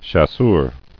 [chas·seur]